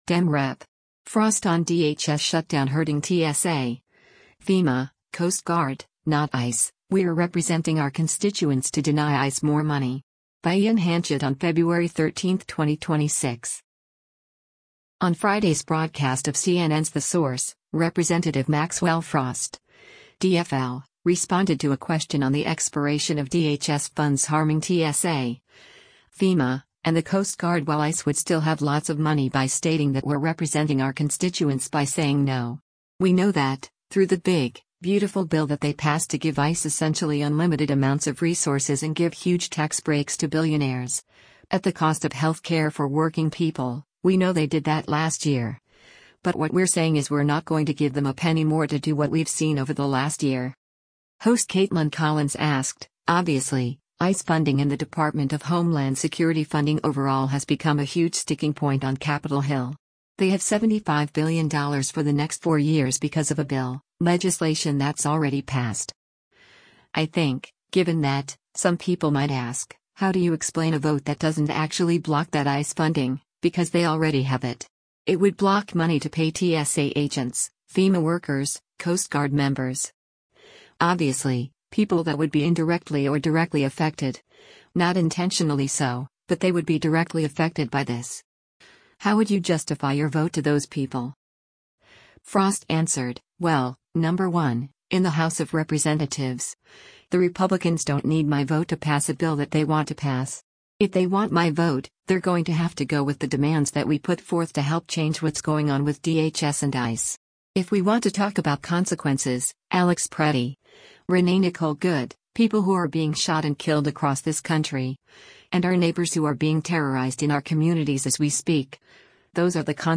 On Friday’s broadcast of CNN’s “The Source,” Rep. Maxwell Frost (D-FL) responded to a question on the expiration of DHS funds harming TSA, FEMA, and the Coast Guard while ICE would still have lots of money by stating that “we’re representing our constituents by saying no. We know that, through the big, beautiful bill that they passed to give ICE essentially unlimited amounts of resources and give huge tax breaks to billionaires, at the cost of health care for working people, we know they did that last year, but what we’re saying is we’re not going to give them a penny more to do what we’ve seen over the last year.”